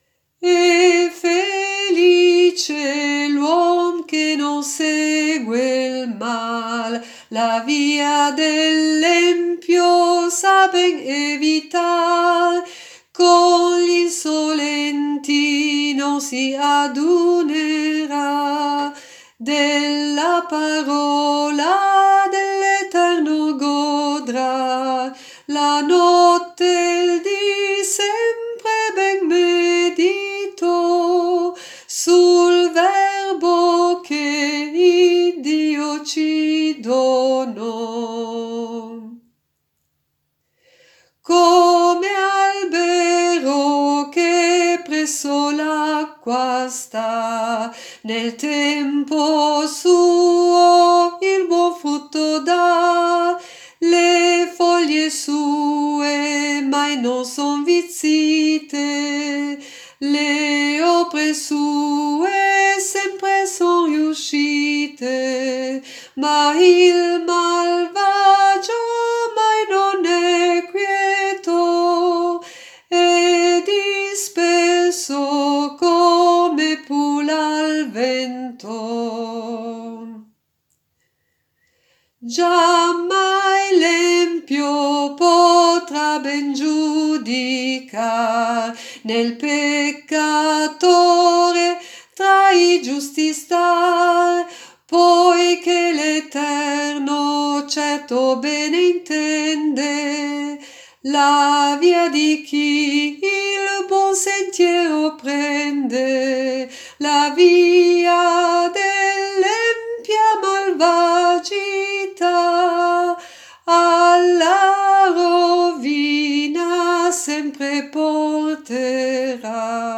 Salmi cantati
sulle musiche del Salterio Ginevrino